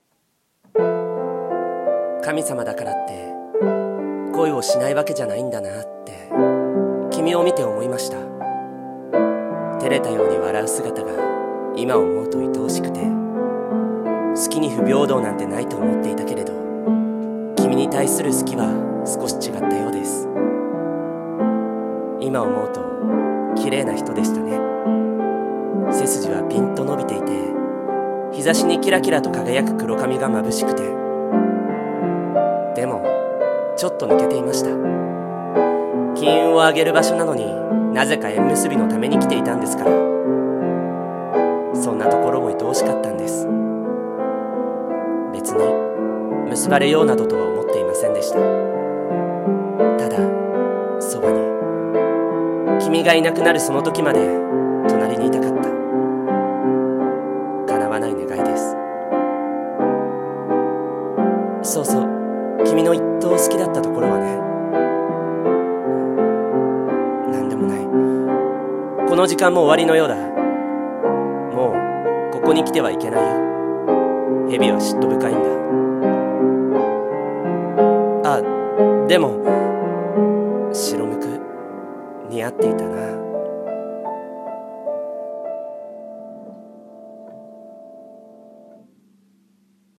【一人声劇】薄哀色の恋